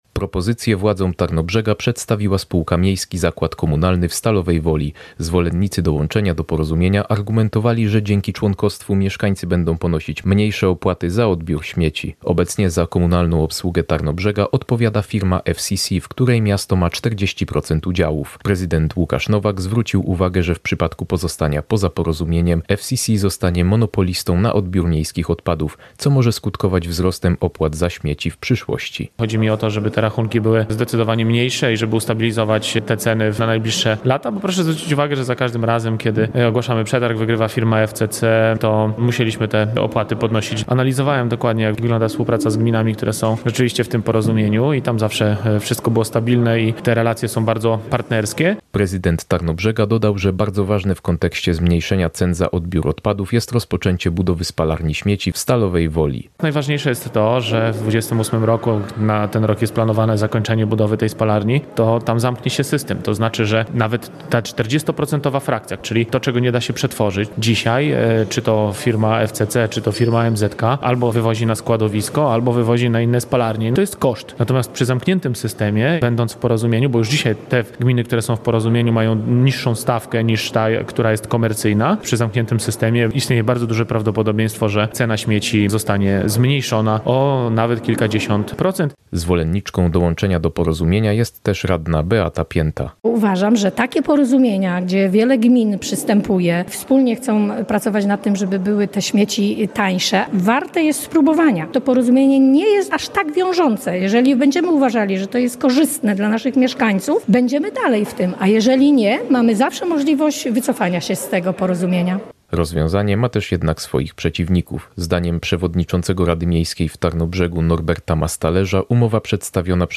Radna Beata Pięta, zwolenniczka rozwiązania, podkreśla, że członkostwo w porozumieniu warto rozważyć.
Z kolei radny Damian Szwagierczak jest przeciwny.